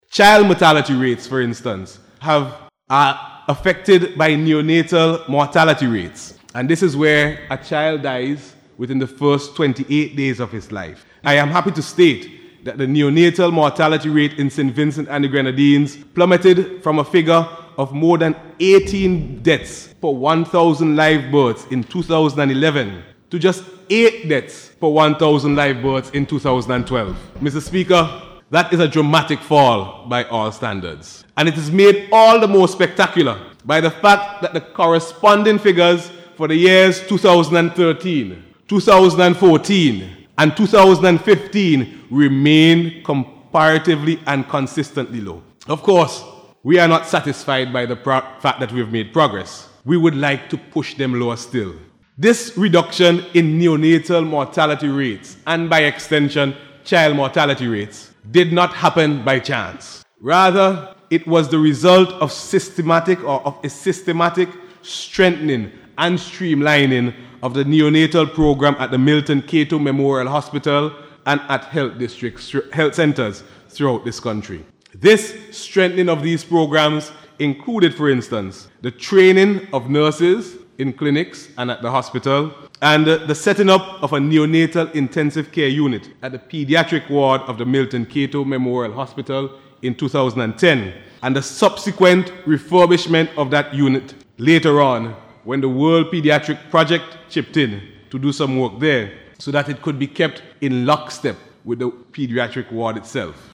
He made the point during his contribution to the debate on the 2016 Appropriation Bill in Parliament this week.